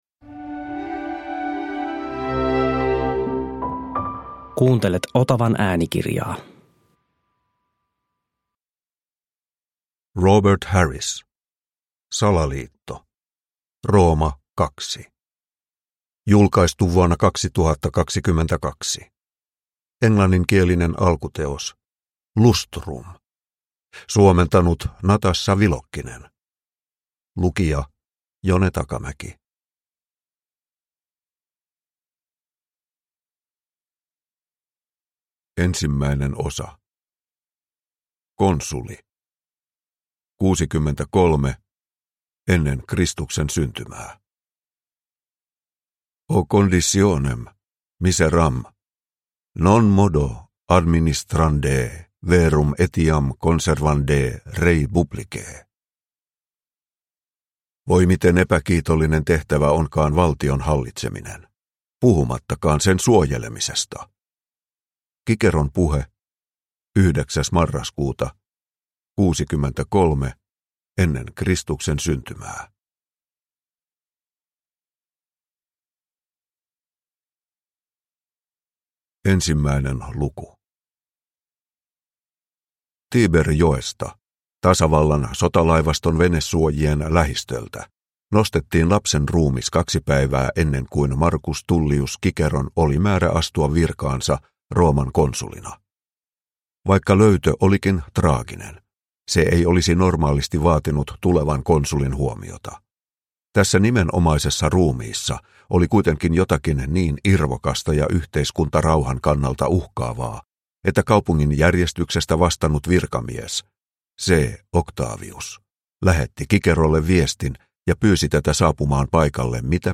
Salaliitto – Ljudbok – Laddas ner